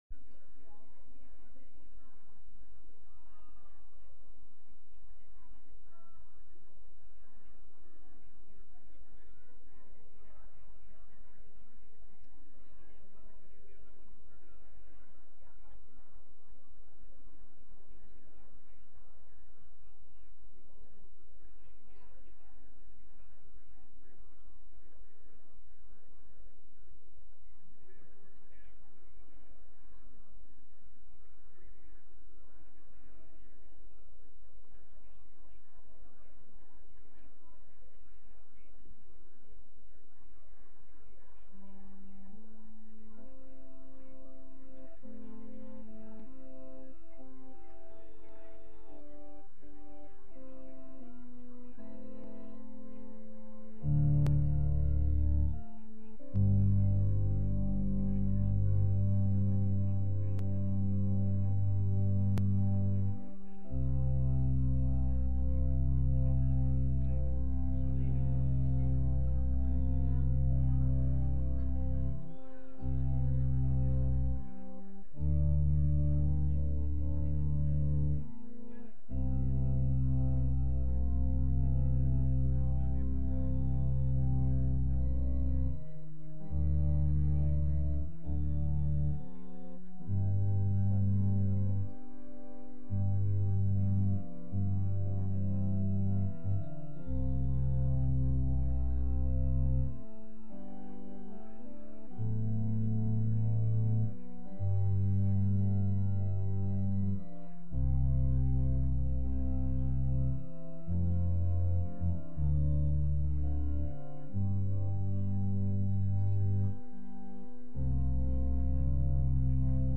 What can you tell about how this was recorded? From Series: "Sunday Worship" Sunday-Service-2-6-22.mp3